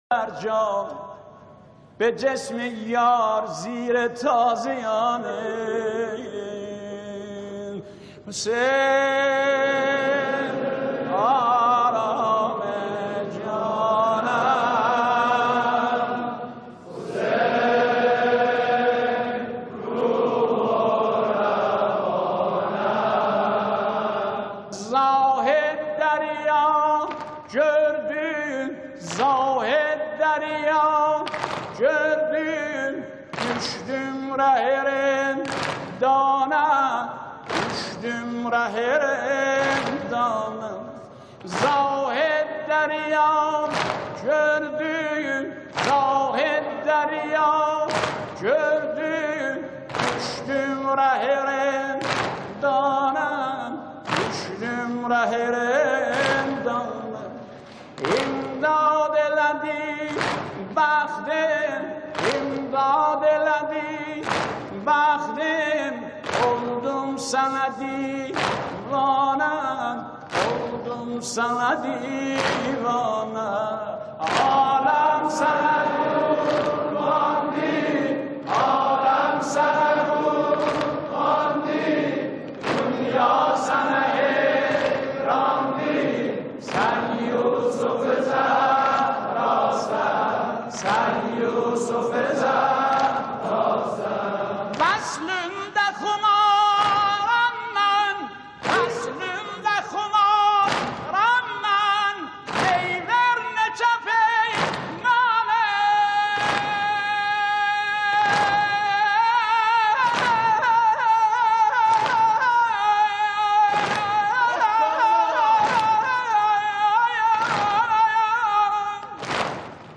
متن نوحه